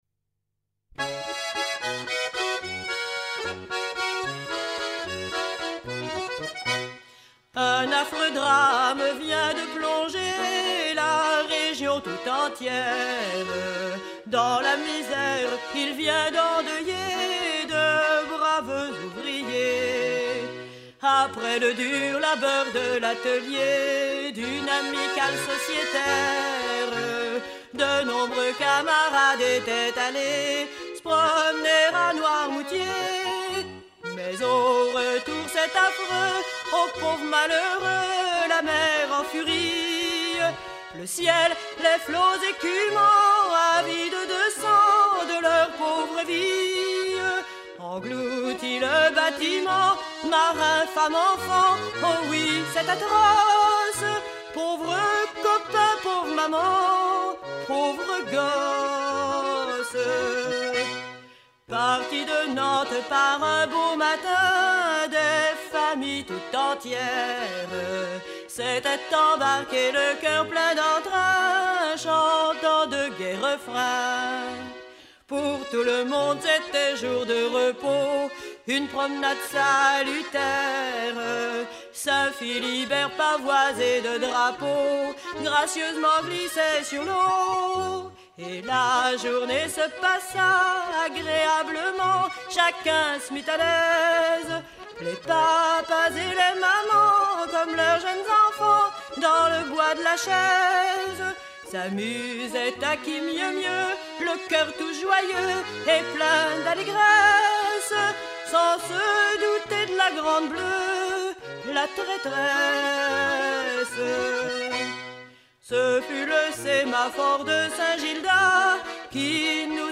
Usage d'après l'informateur circonstance : maritimes ;
Genre strophique
Pièce musicale éditée